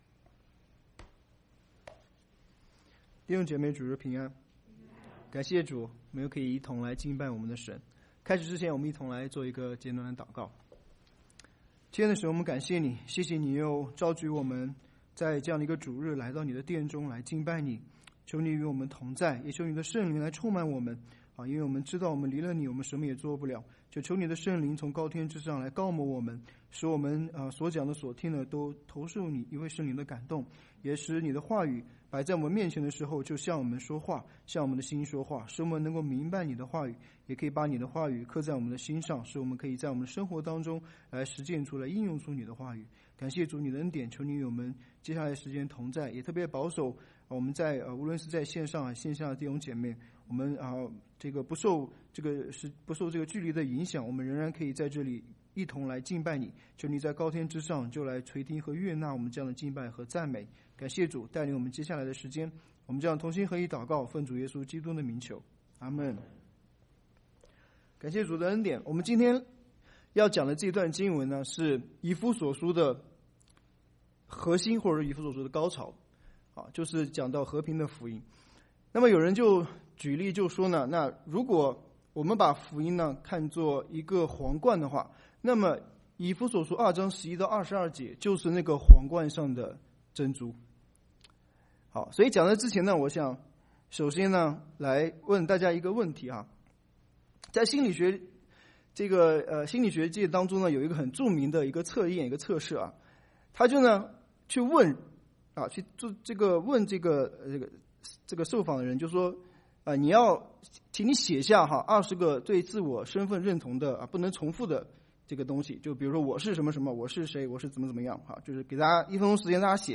崇拜講道錄音